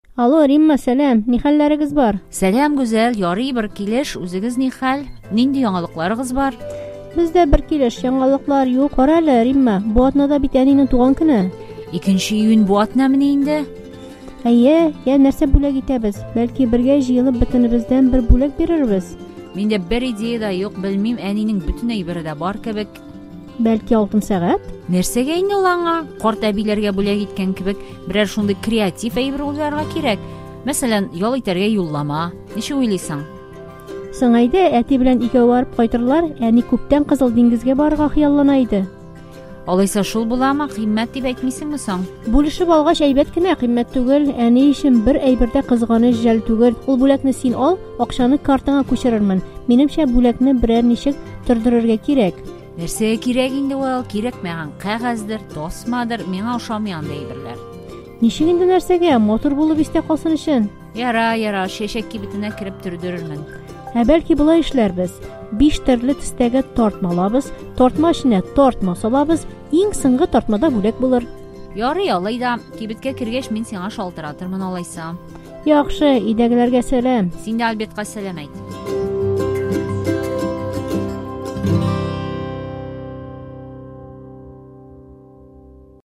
Диалог "Креатив булсын"